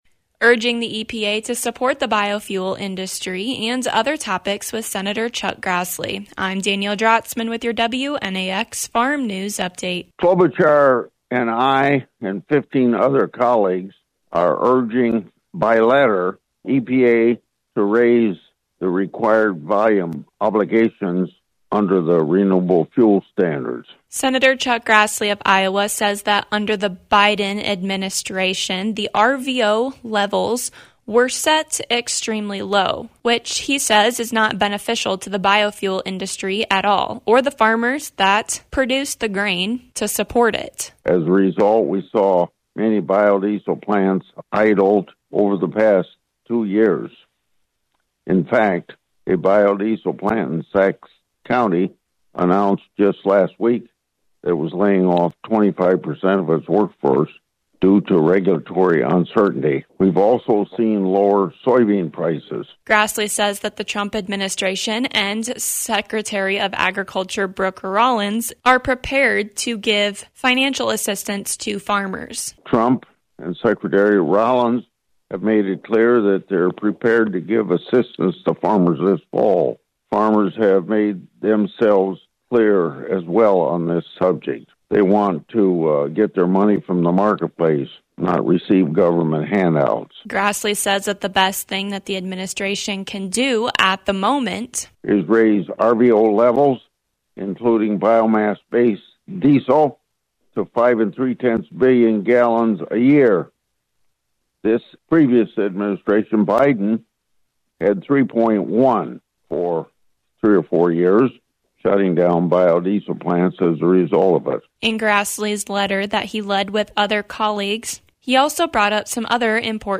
Senator Chuck Grassley talks about his priorities for the biofuel industry.